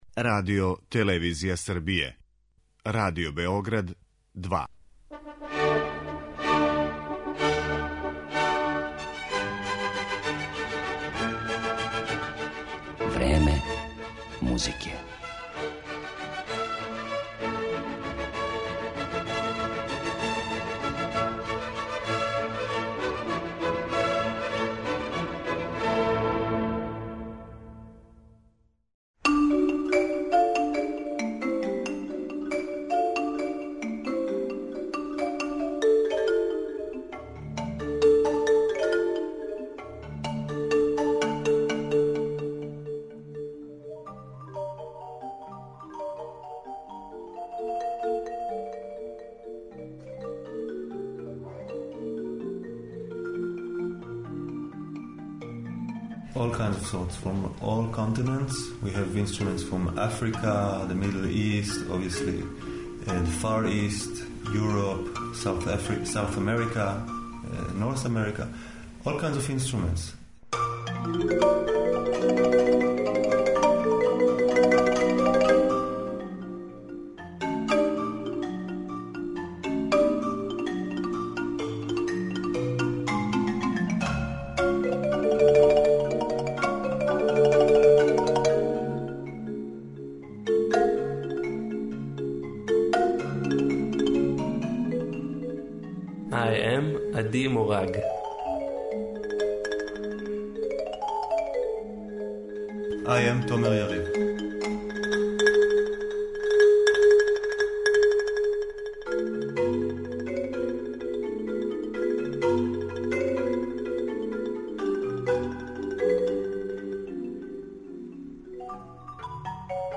Овом изузетном ансамблу посвећено је данашње Време музике. Емитоваћемо интервју снимљен са извођачима приликом једног од њихових гостовања у Београду, а изводиће дела Римког-Корсакова, Дормана, Вестлејка и Чајковског.